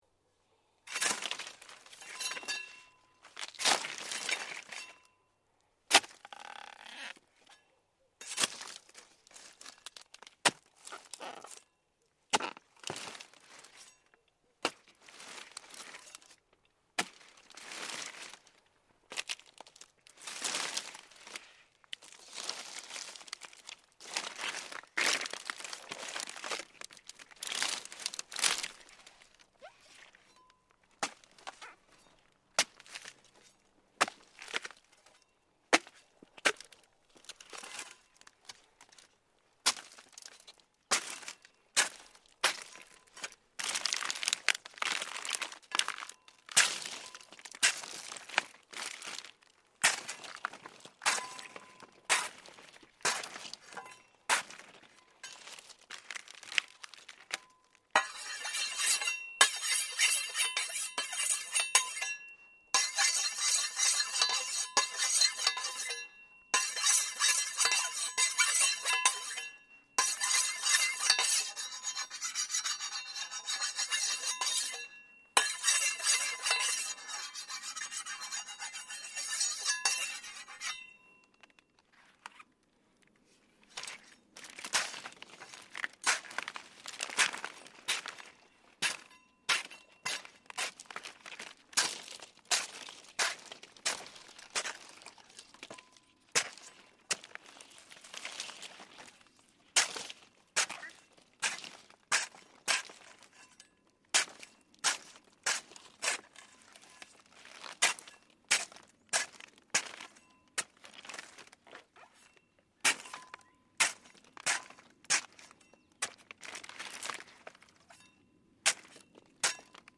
Jimadores cutting agave at Tequila
Stereo 48kHz 24bit.
——————— This sound is part of the Sonic Heritage project, exploring the sounds of the world’s most famous sights.